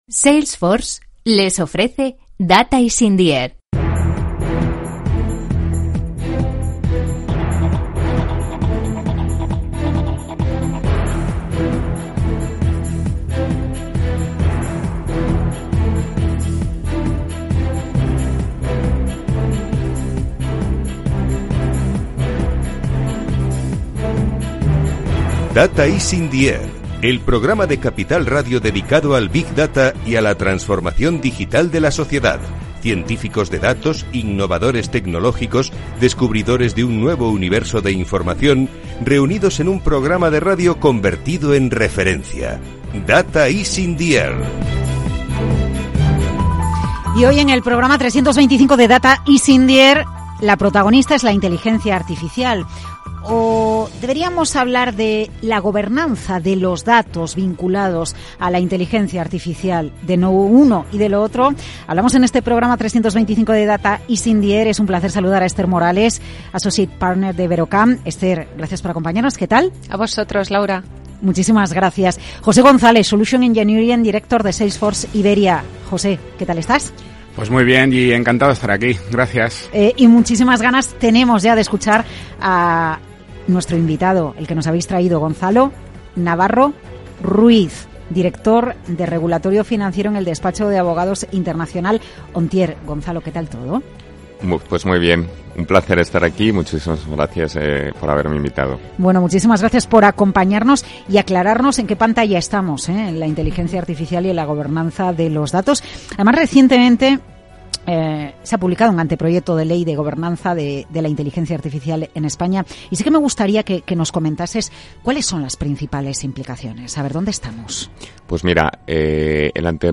Data is in the Air es el primer programa de radio en español dedicado a la aplicación del Big Data y la Inteligencia Artificial en la empresa y en la vida cotidiana.
Cada semana, responsables tecnológicos y de datos de todo tipo de sectores explican de primera mano los procesos de transformación digital en sus compañías.